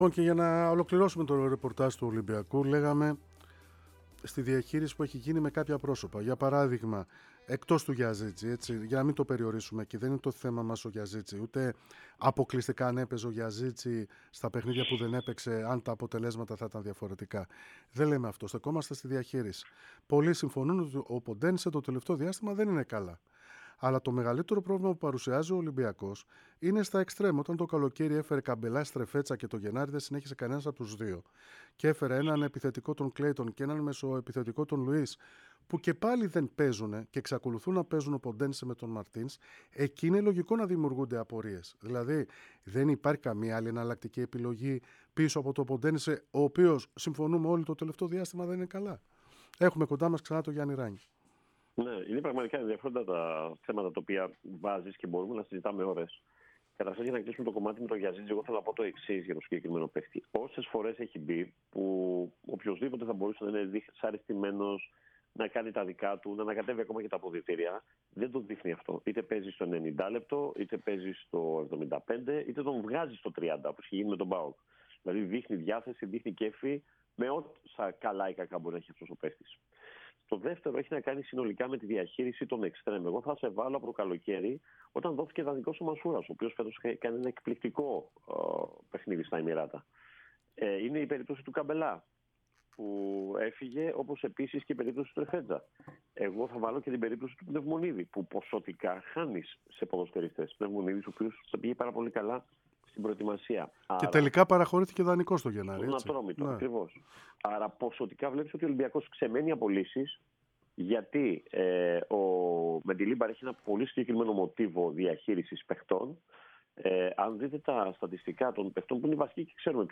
μίλησε στον αέρα της ΕΡΑ ΣΠΟΡ και στην εκπομπή "3-5-2"